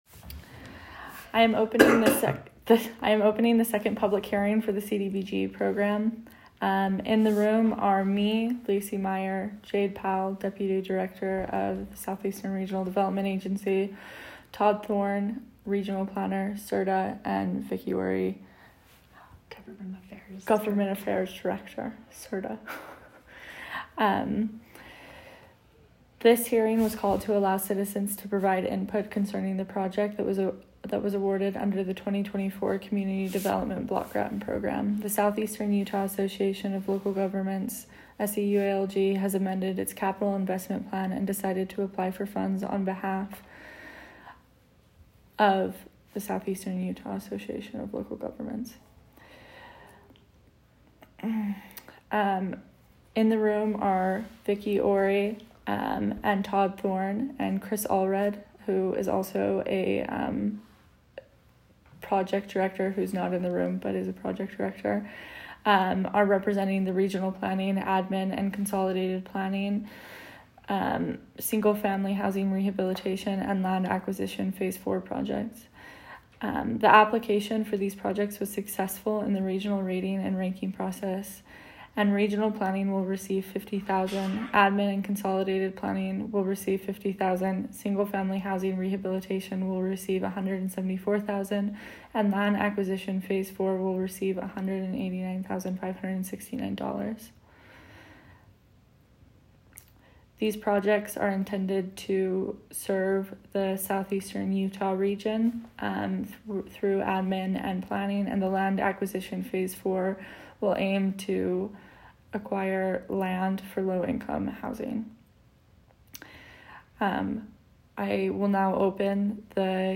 2nd CDBG Public Hearing.m4a